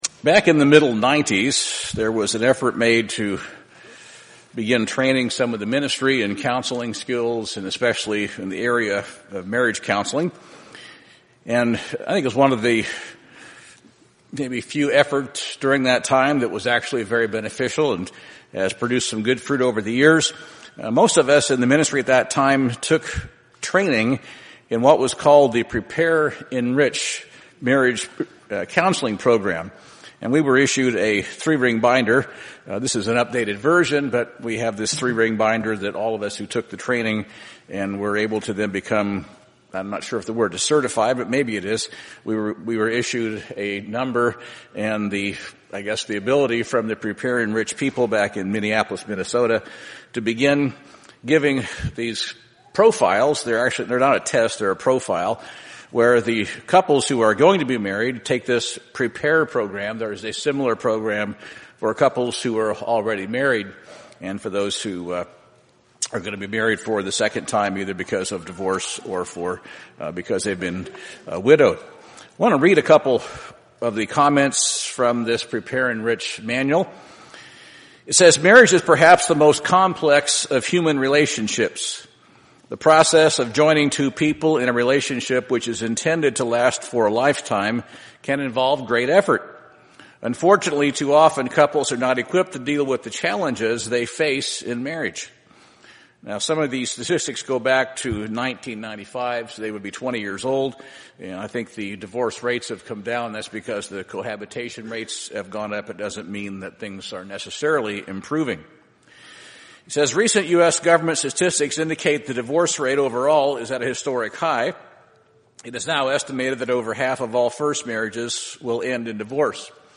There are 4 basis of marriage: Conflicted, Traditional, Harmonious, and Vitalized. These are explained in this sermon on marriage as well as some examples of good and bad marriages listed in the Bible.